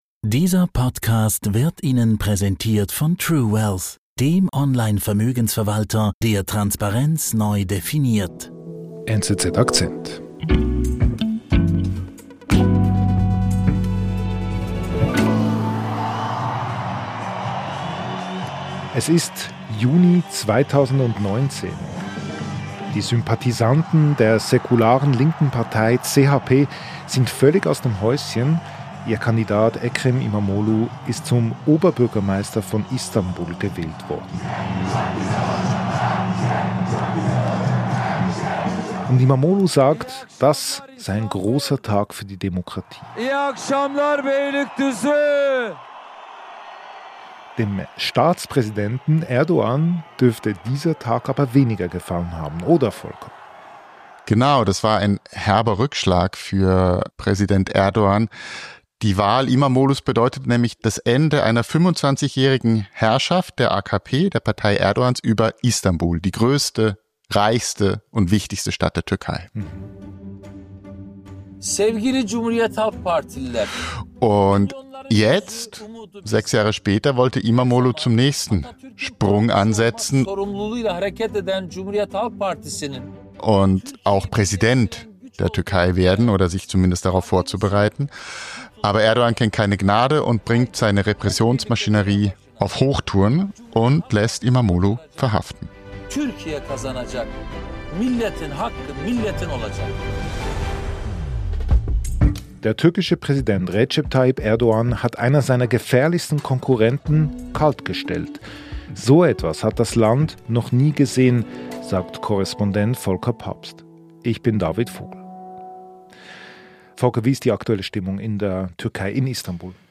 Täglich erzählen NZZ-Korrespondentinnen und Redaktoren, was sie bewegt: Geschichten aus der ganzen Welt inklusive fundierter Analyse aus dem Hause NZZ, in rund 15 Minuten erzählt.